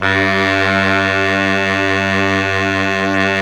SAX 2 BARI0J.wav